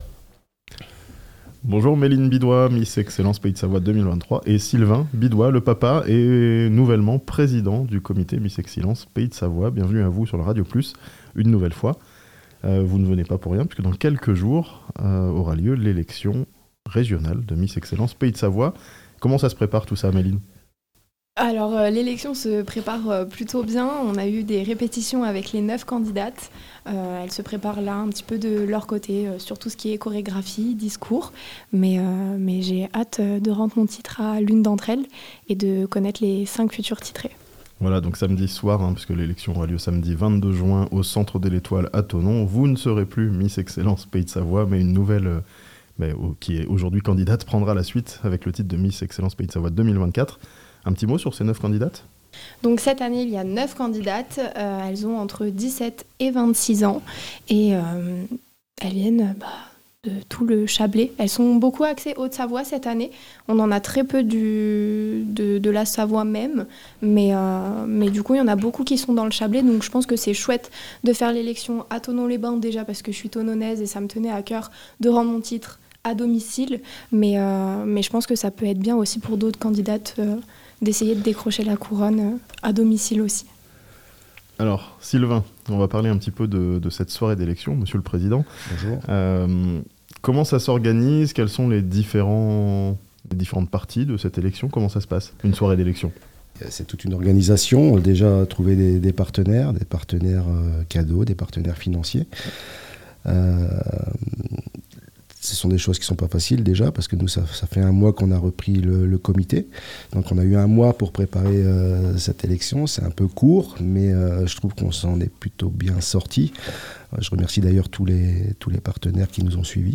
Miss Excellence Pays de Savoie 2024 sera élue à Thonon samedi 22 juin (interview)